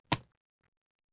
splats1m.mp3